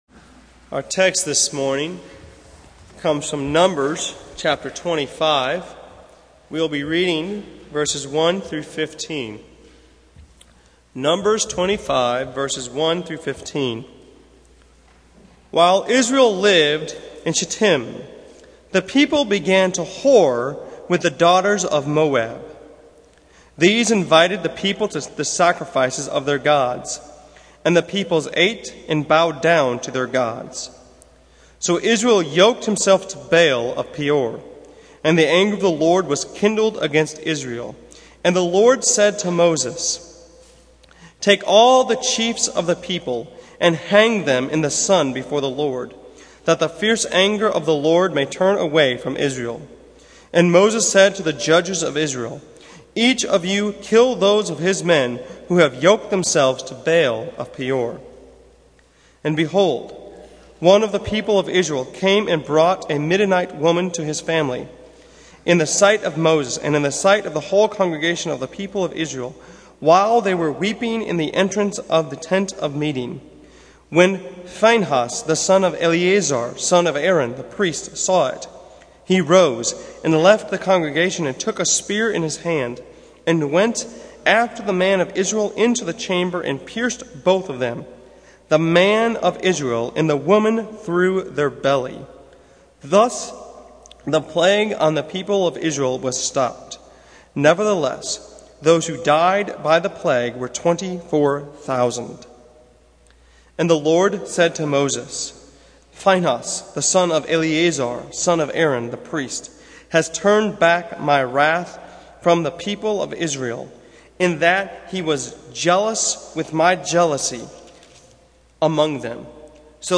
Series: Roadblocks in the Wilderness Service Type: Sunday Morning